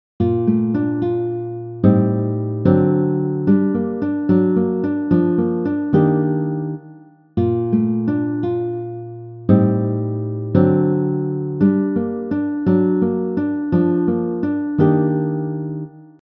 You’ll hear the regular tempo and a slower tempo to help you learn each example.
Progression 2 chords are Bb Major 7, A minor 7 b5, D 7, G minor, G minor/F#, G minor/F, and E minor 7 b5.
The E minor 7 b5 chord in measure 4 finalizes a cliché chord movement starting on the G minor in measure 3.
minor 7 b5 chord progression example 2 guitar